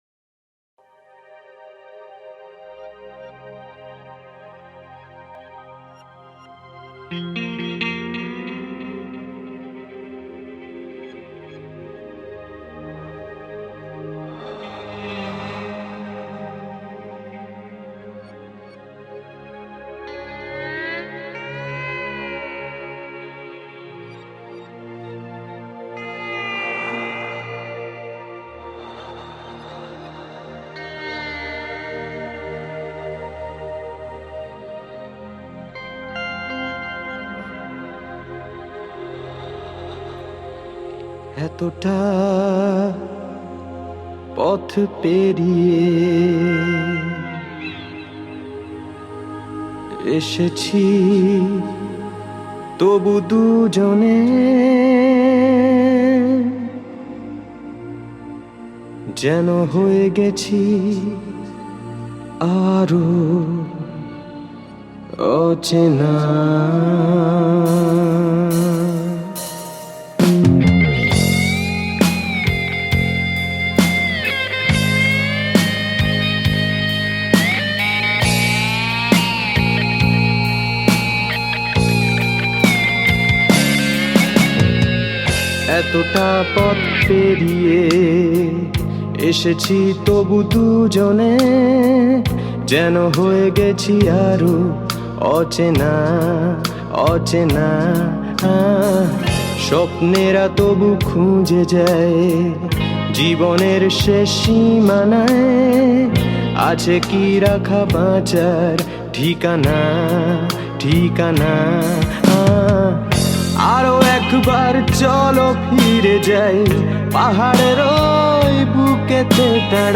hard music
band song
sad song